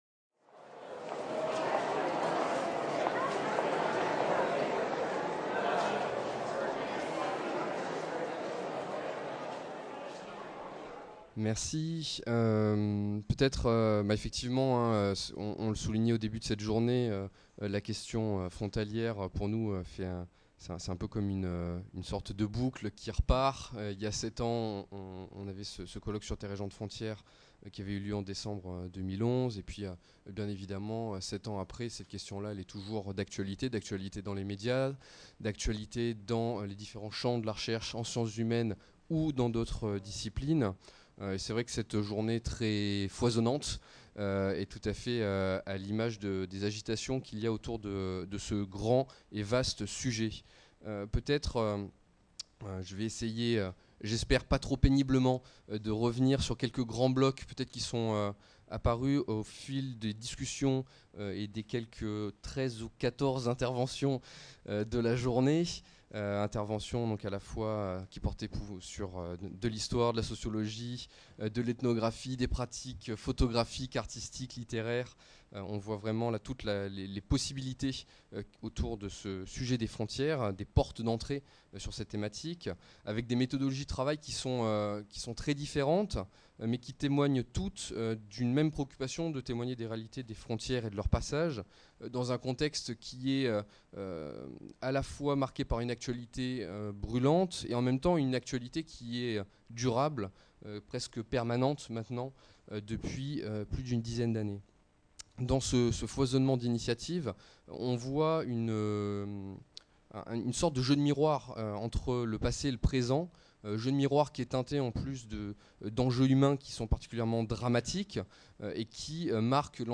14. Conclusions de la journée d'études
Journée d’étude organisée par l’Urmis et l’Observatoire des Migrations dans les Alpes-Maritimes à la MSHS de Nice, le jeudi 6 décembre 2018.